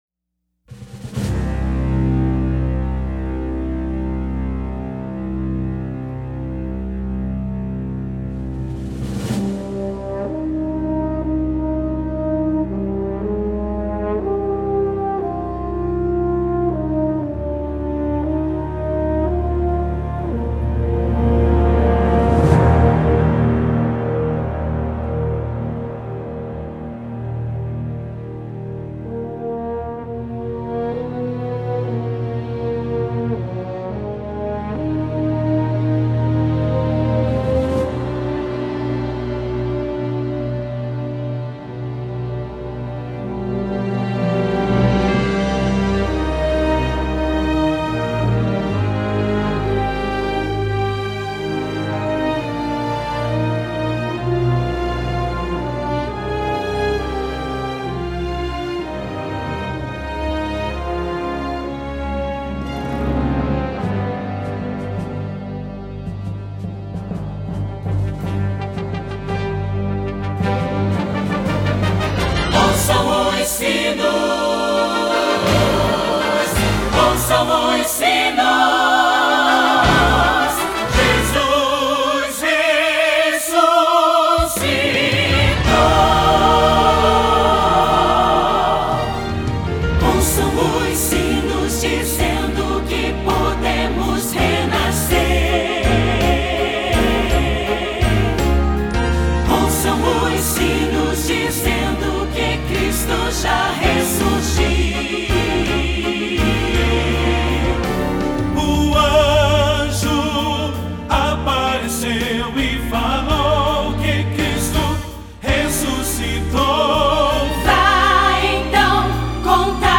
cantata de Páscoa